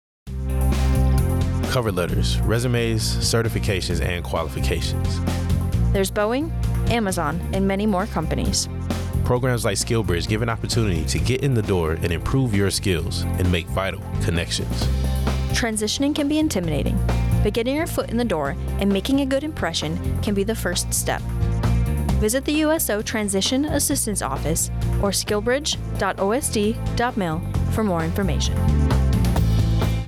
AFN Naples Radio Spot - SkillBridge